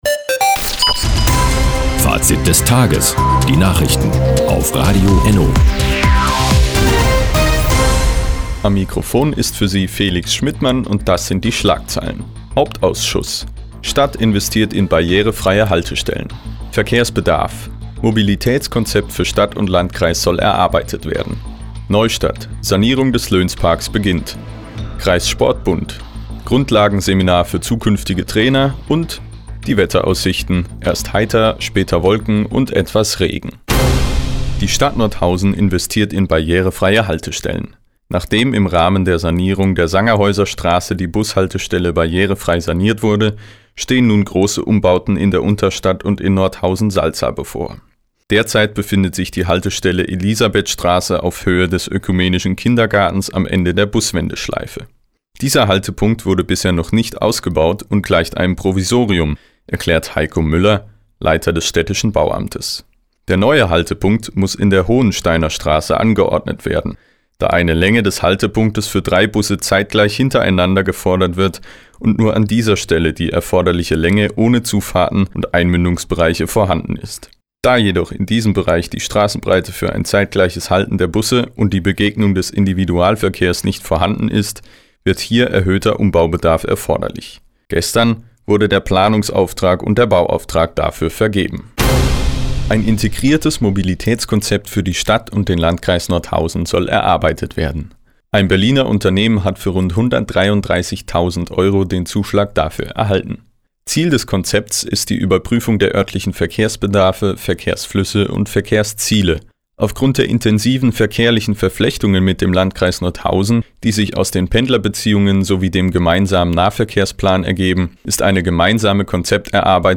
Do, 16:58 Uhr 03.09.2020 Neues von Radio ENNO Fazit des Tages Anzeige Refinery (lang) Seit Jahren kooperieren die Nordthüringer Online-Zeitungen und das Nordhäuser Bürgerradio ENNO. Die tägliche Nachrichtensendung ist jetzt hier zu hören.